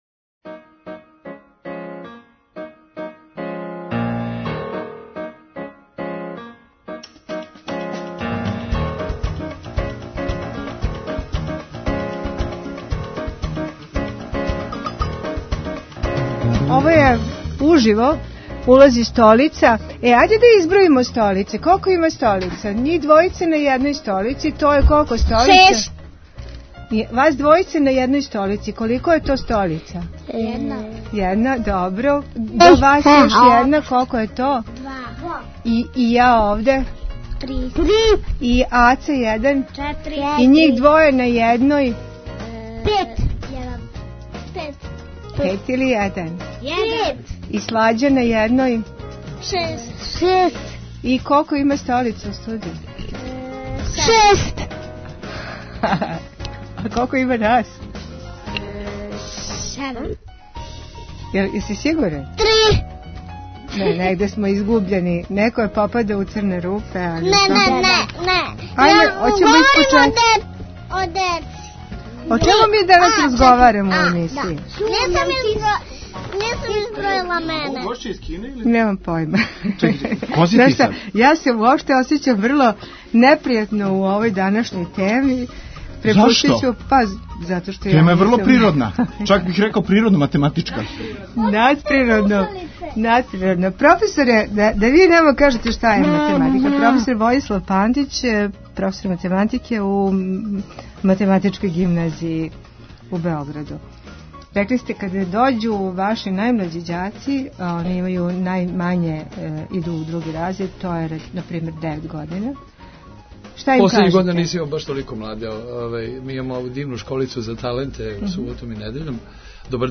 Говоре предшколци из "Весељка", млади музичари и професор